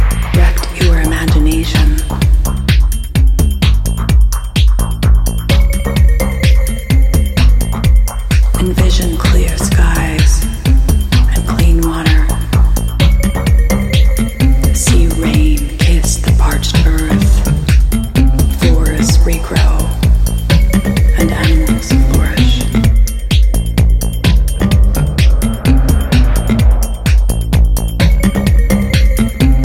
From hypnotic rhythms to enveloping melodies